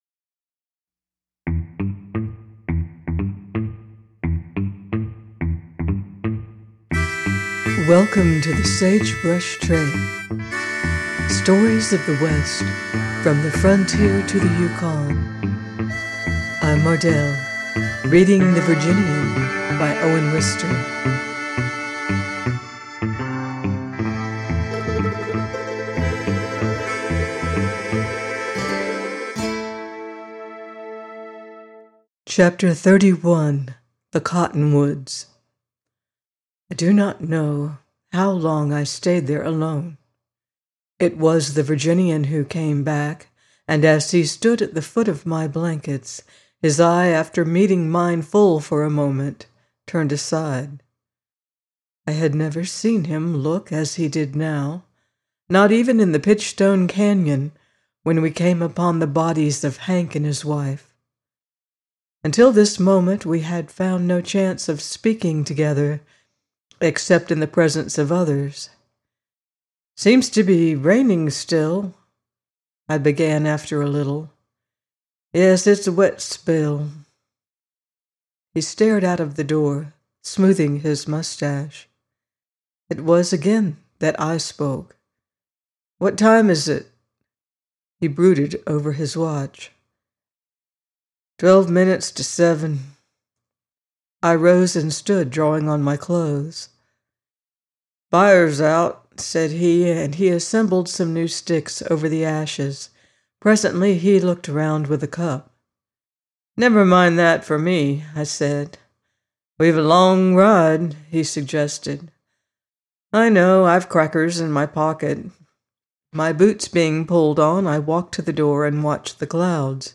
The Virginian - by Owen Wister - audiobook